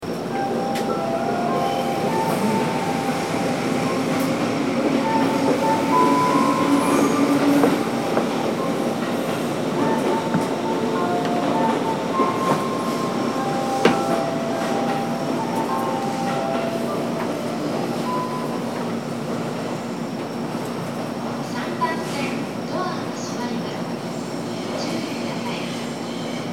久喜駅　Kuki Station ◆スピーカー：小VOSS,ユニペックス箱型
3番線発車メロディー